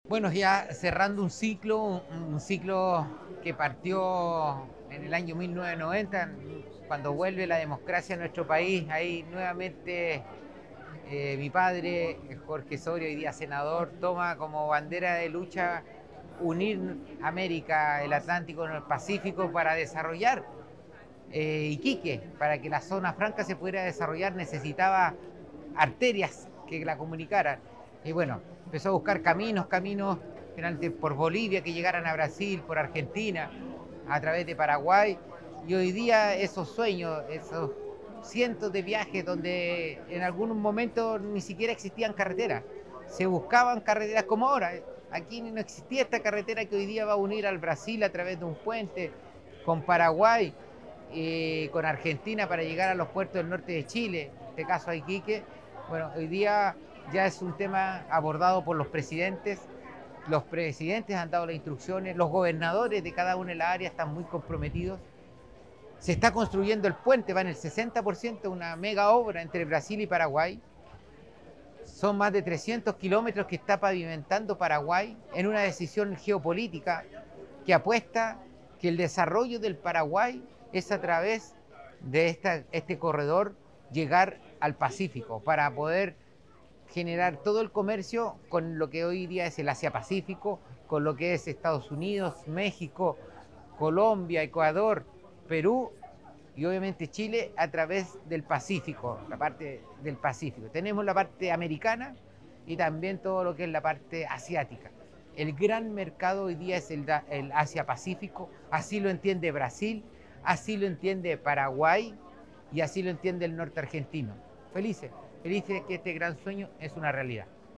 CUNA-ALCALDE-MAURICIO-SORIA-EN-BRASIL-1.mp3